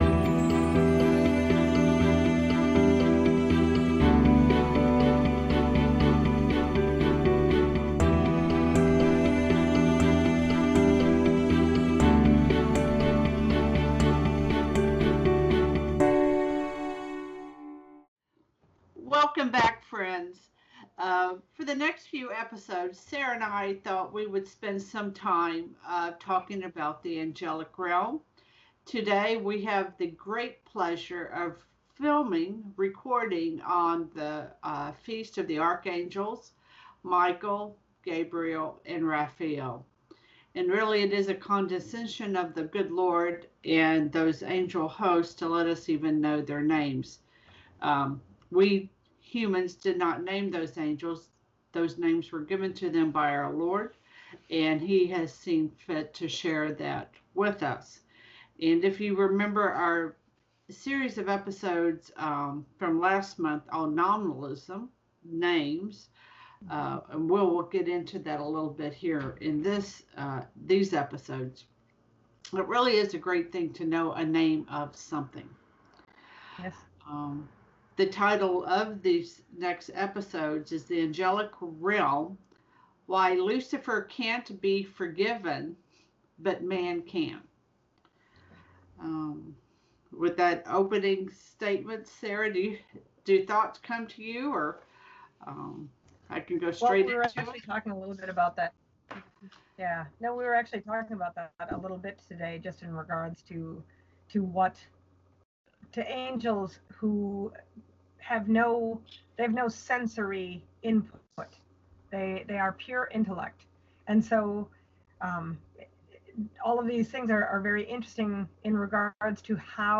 Filmed on Archangels Feast Day September 29